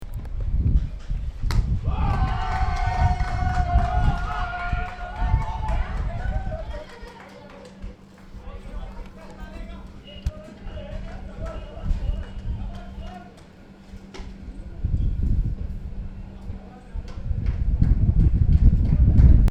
criquet.mp3